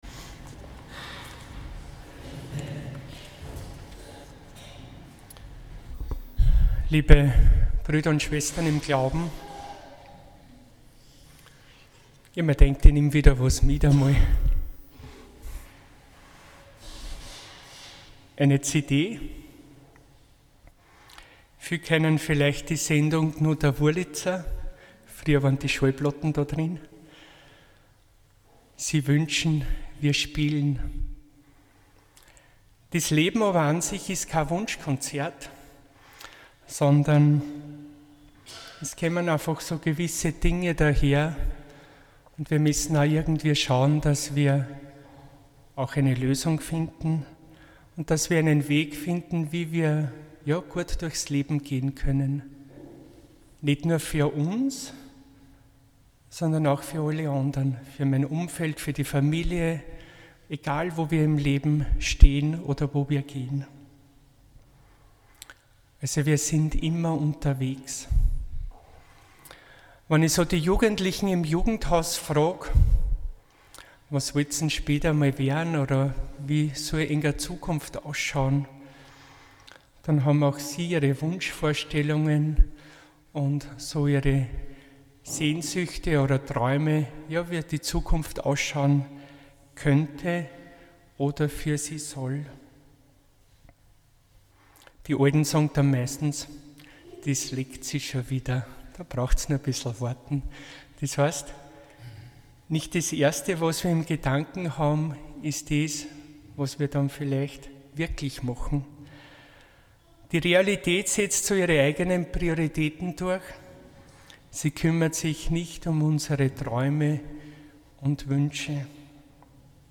Predigt 17. Sonntag im Jahreskreis